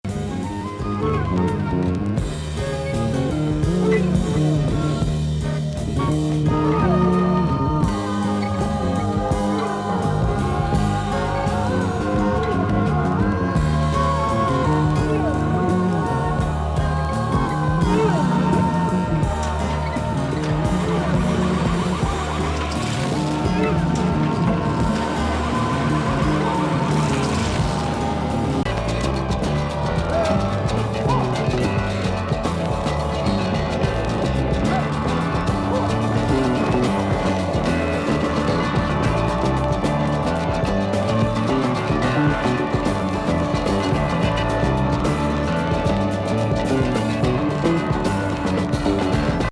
サウンド・コラージュに漂うアシッディ・フォーク
サイケ・ファンク